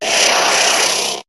Cri de Kadabra dans Pokémon HOME.